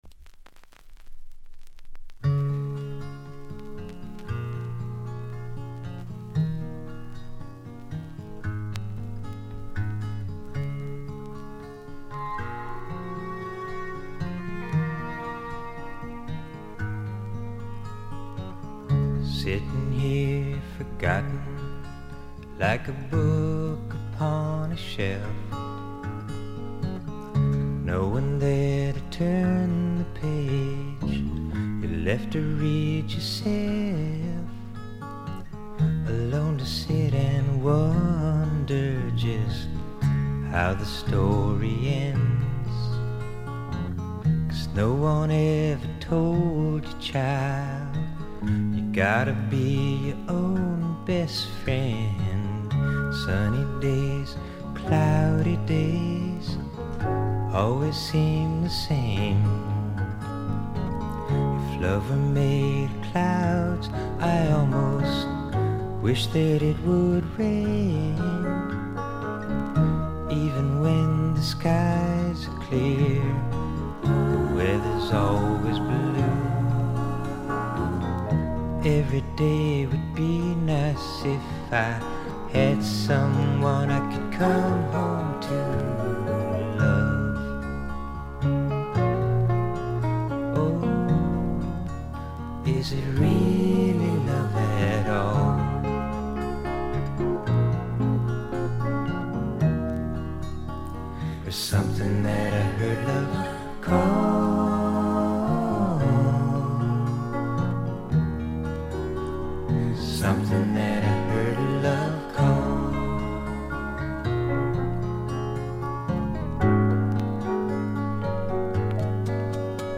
部分試聴ですが、軽いチリプチ、散発的なプツ音少し。
控えめな演奏をバックに複雑な心象風景を淡々と描いていく歌声が本作の最大の魅力でしょう。
試聴曲は現品からの取り込み音源です。